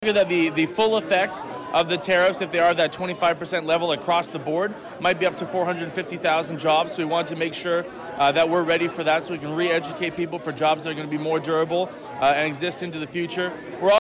Bay of Quinte riding MPP Tyler Allsopp speaking at a breakfast meeting of the Belleville Chamber of Commerce Friday March 28 2025 at Sans Souci.